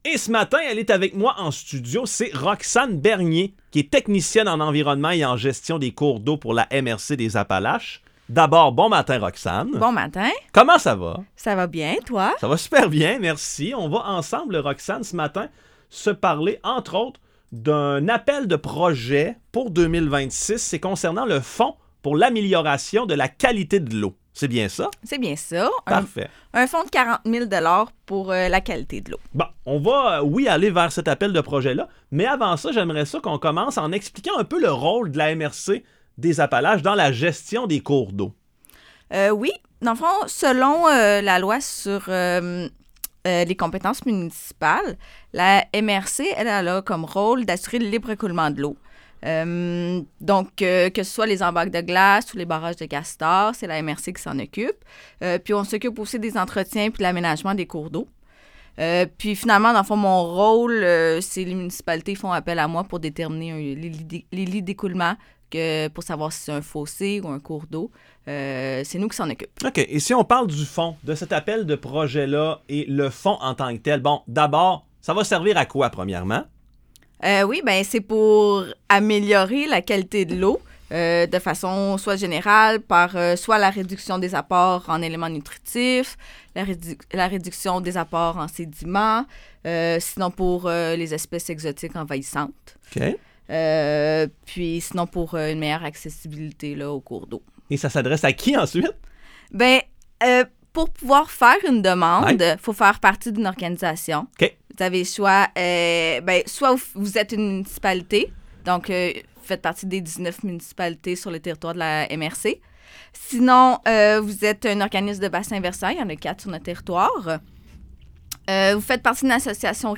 Chroniques radio
Les chroniques radio de la MRC des Appalaches sont diffusées à la station de radio VIVA 105,5, les mercredis chaque deux semaines, vers 8h00.